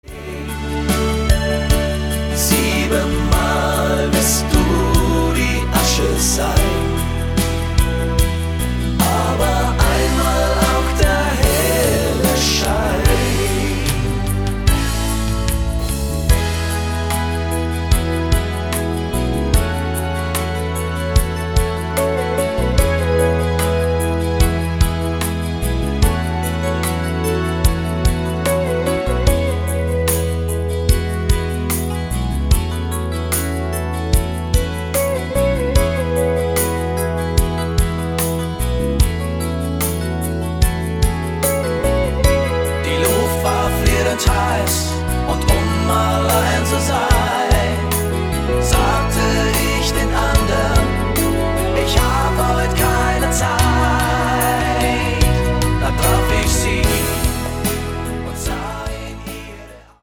Seine größten Balladen